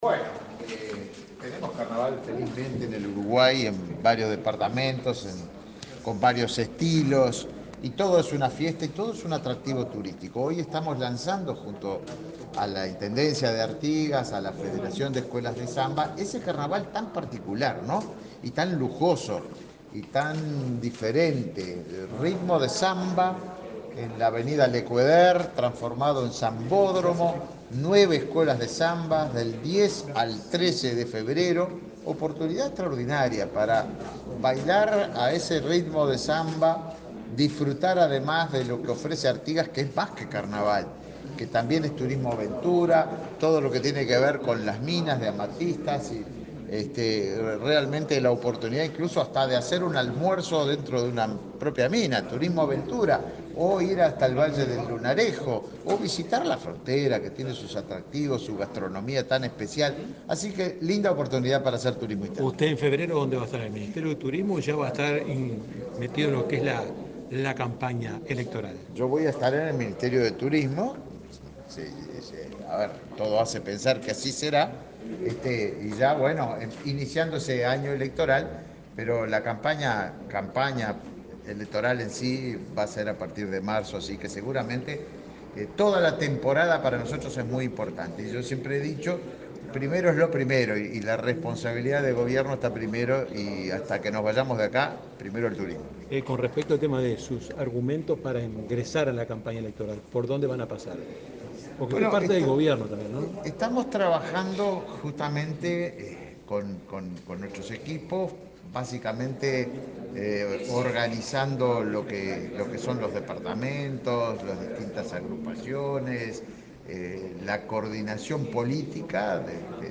Declaraciones del ministro de Turismo, Tabaré Viera
El ministro de Turismo, Tabaré Viera, dialogó con la prensa, luego de participar del lanzamiento del Carnaval de Artigas, realizado este jueves 7 en